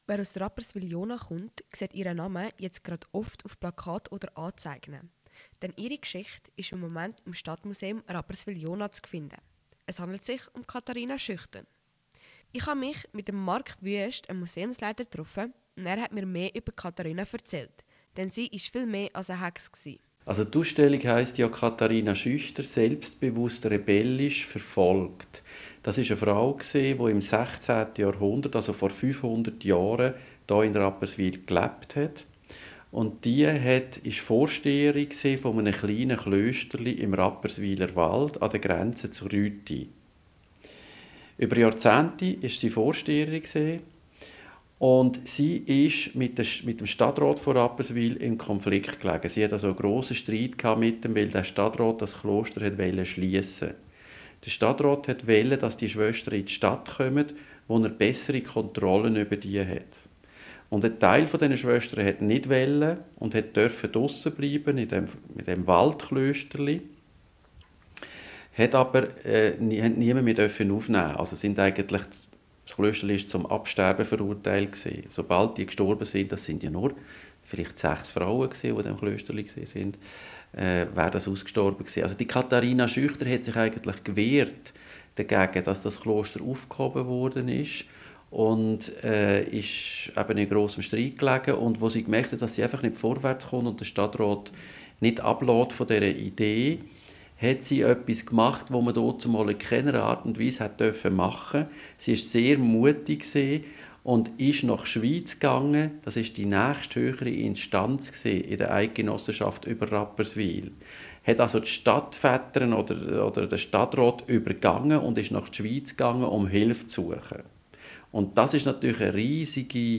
Ich besuchte die Ausstellung im Stadtmuseum in Rapperswil-Jona